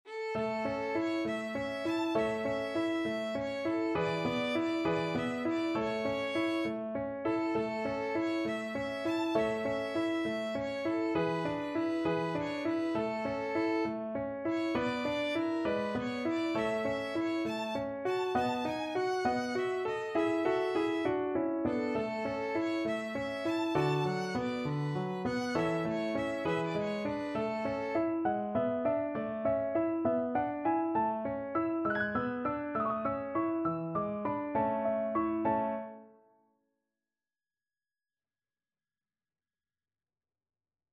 ~ = 100 Fršhlich
6/8 (View more 6/8 Music)
Classical (View more Classical Violin Music)